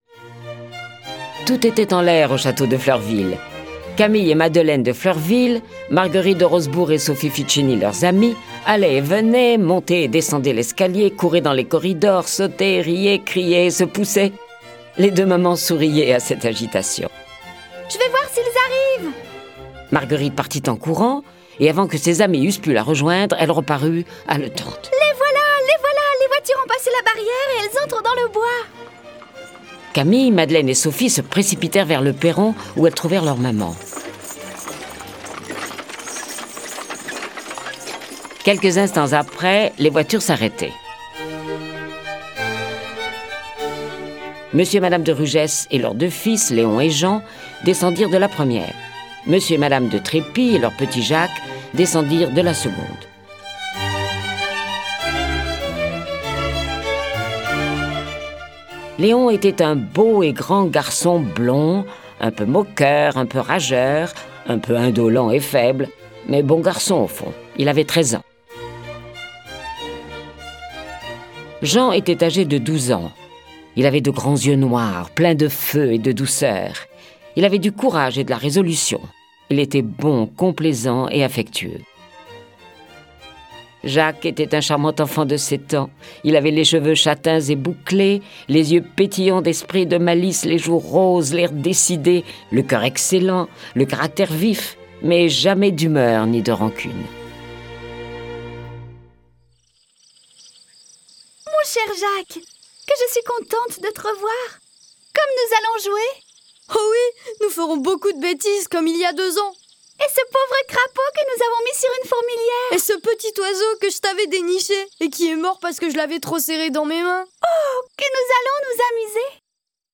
Cette version sonore des aventures de Paul, Madeleine, Camille, Marguerite et Sophie est animée par treize voix et accompagnée de près de trente morceaux de musique classique.